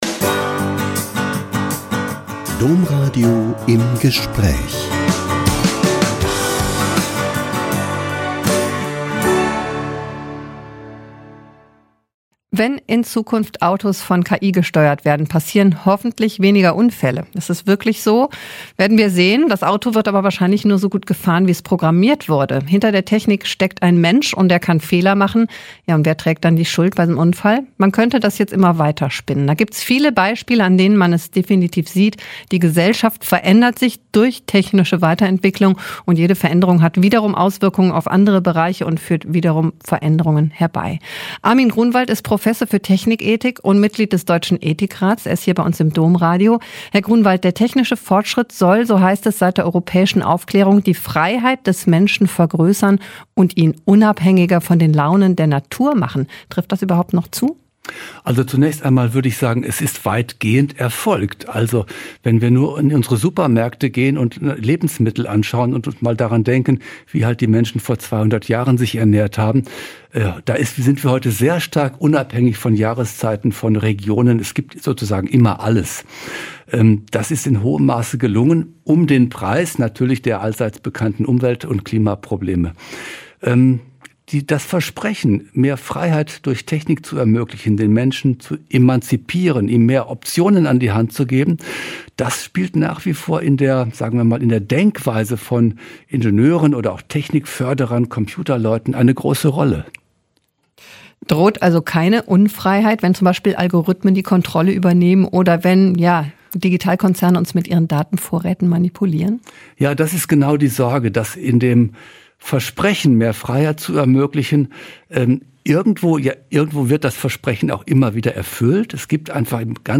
Technik-Ethiker spricht über Chancen Künstlicher Intelligenz - Ein Interview mit Armin Grunwald (Professor am Karlsruher Institut für Technologie und Mitglied des Deutschen Ethikrates) ~ Im Gespräch Podcast